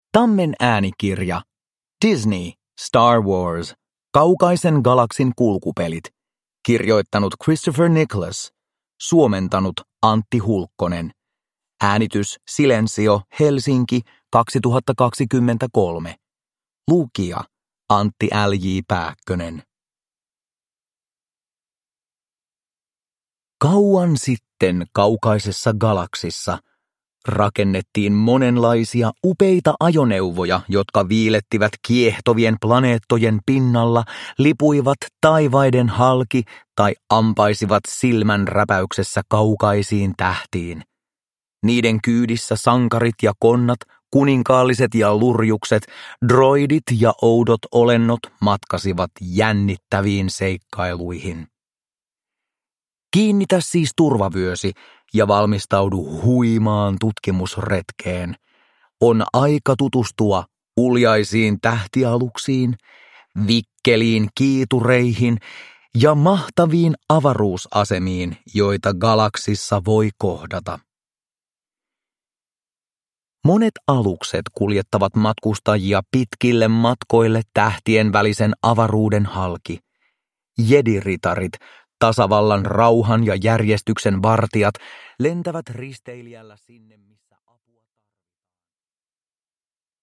Star Wars. Kaukaisen galaksin kulkupelit – Ljudbok – Laddas ner